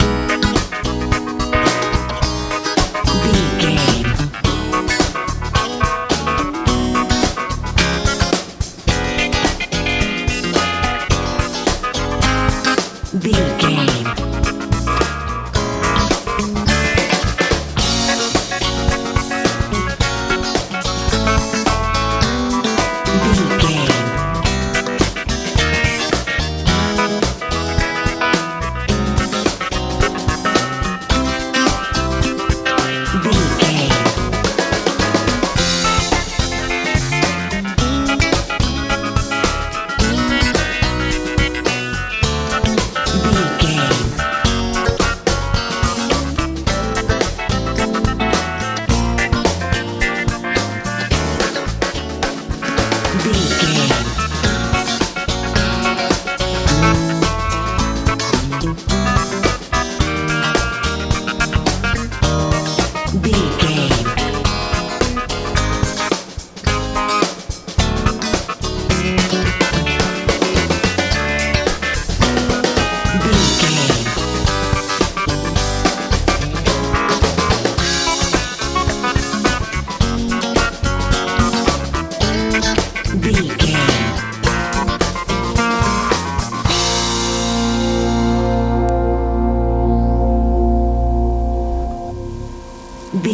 70s funk
Ionian/Major
A♭
groovy
funky
electric guitar
piano
bass guitar
drums
sweet